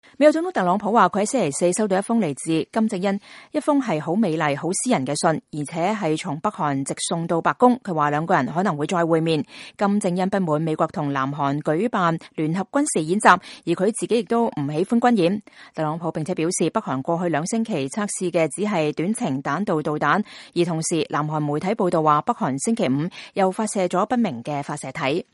8月9號星期五上午特朗普總統在白宮南草坪告訴記者：“我昨天收到一封來自金正恩的美麗的信。是派人送來的。是一封很正面的信。 ”